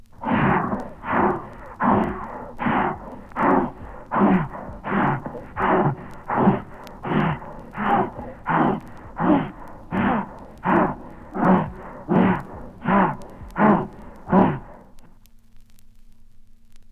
• the hell hound panting.wav
the_hell_hound_panting_Obu.wav